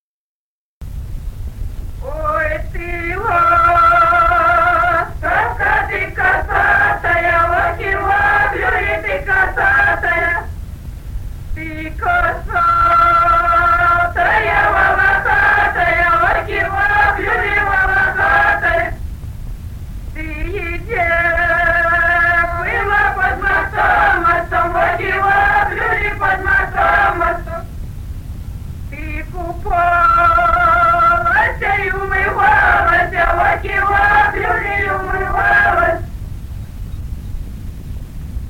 Народные песни Стародубского района «Ой, ты ластовка», юрьевская таночная.
1963 г., с. Курковичи.